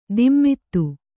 ♪ dimmittu